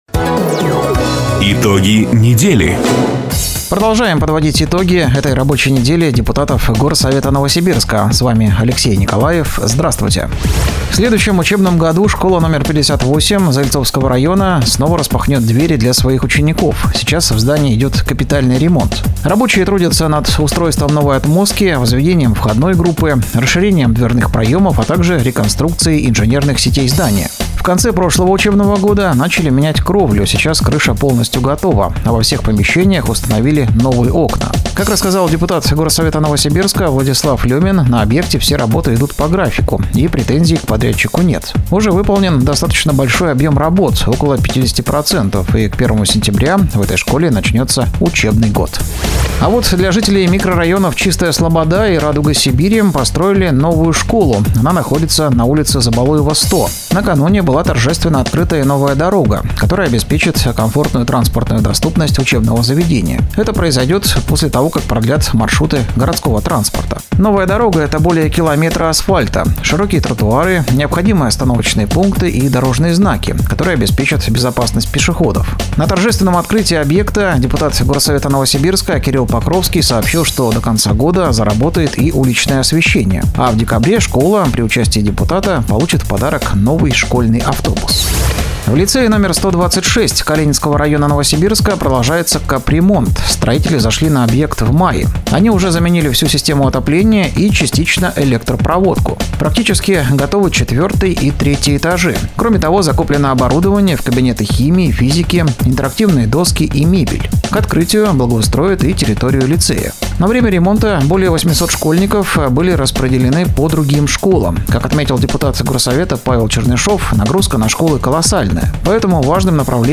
Запись программы "Итоги недели", транслированной радио "Дача" 17 ноября 2024 года.